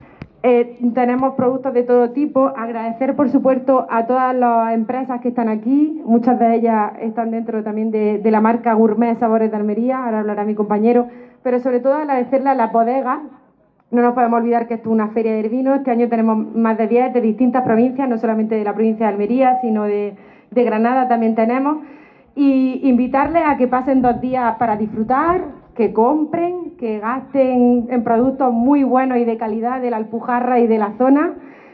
Esta mañana, a las 12 horas, se ha llevado a cabo la inauguración oficial de esta edición con la participación de la alcaldesa de Laujar de Andarax y vicepresidenta de Diputación, Almudena Morales; del diputado de Promoción Agroalimentaria, Carlos Sánchez; de la delgada del Gobierno de la Junta en Almería, Aránzazu Martín, y del delegado de Turismo, Juna José Alonso, junto a otros miembros de la corporación municipal y concejales de otros pueblos.
Almudena-Morales-Feria-Vino.mp3